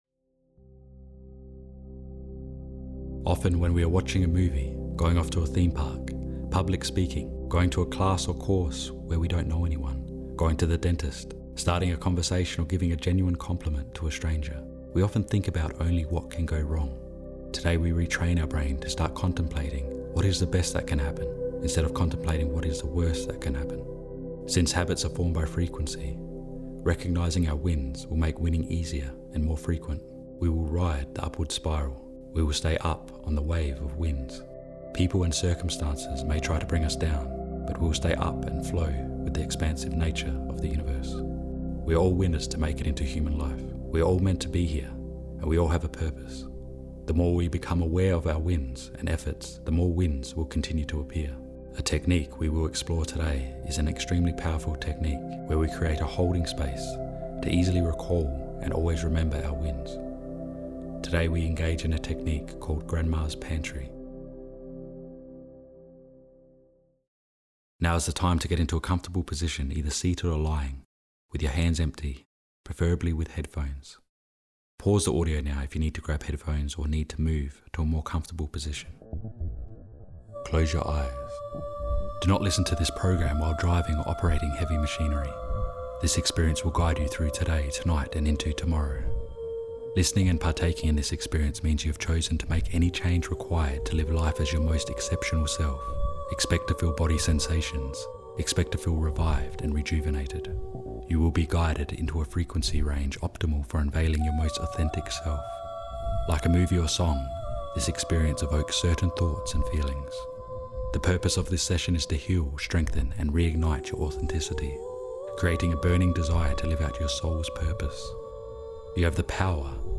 day21-speech-session.mp3